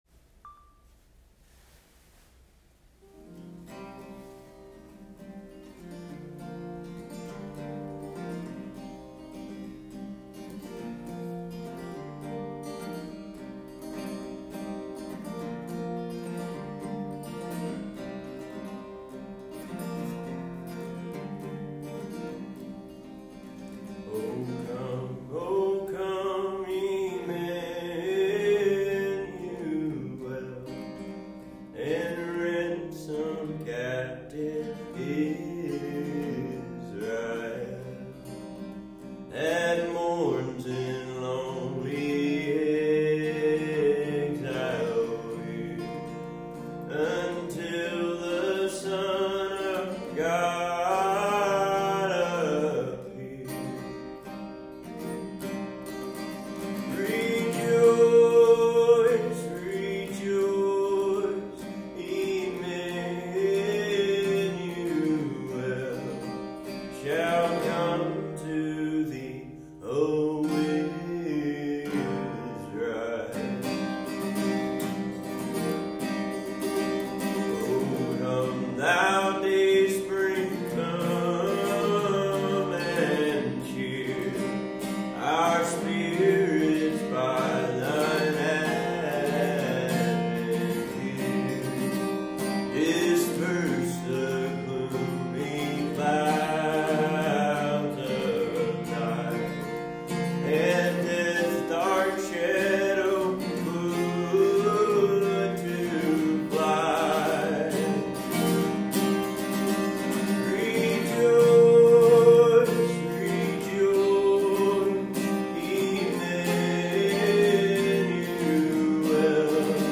These music sessions were recorded in the Chapel on the Dunes.